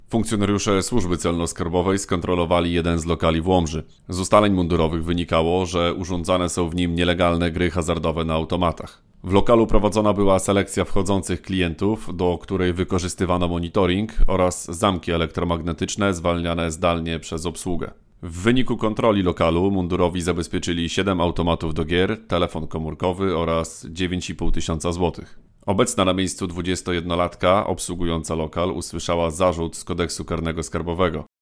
O szczegółach sprawy mówi młodszy rewident